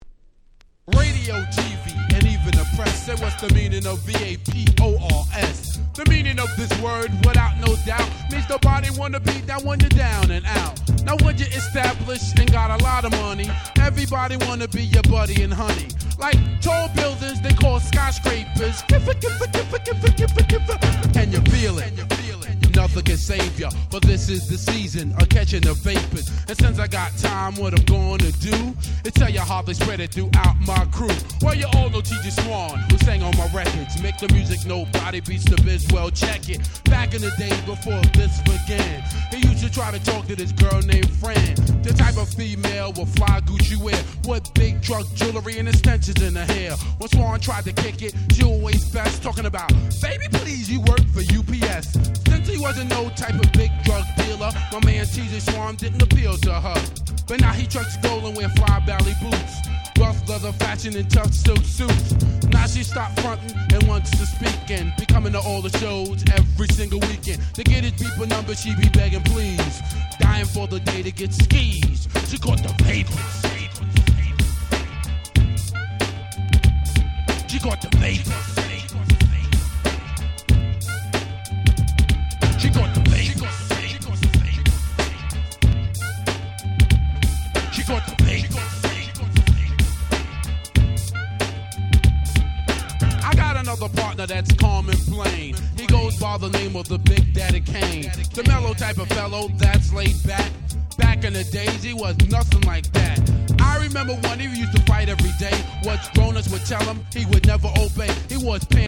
88' Hip Hop Classics !!